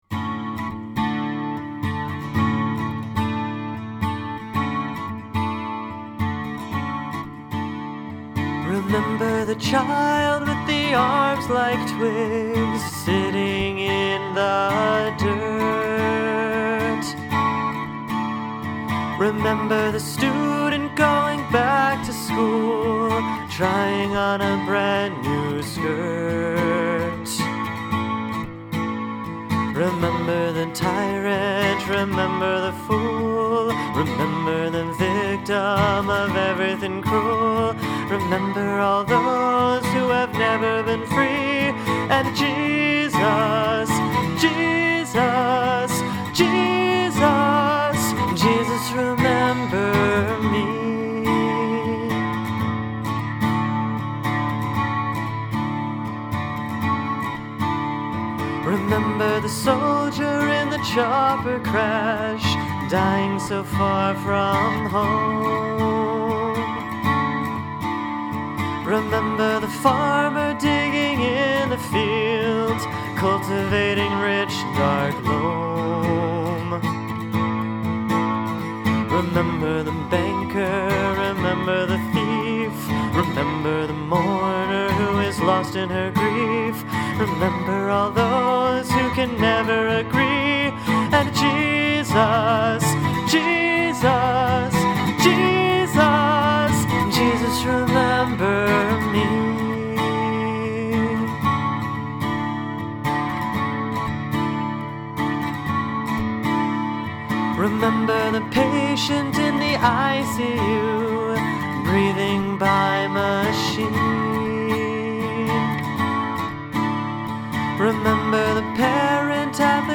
For each song, I gave myself no more than two hours to write it and one to record it. These are by no means polished songs; they are the responses of my heart to Christ crucified.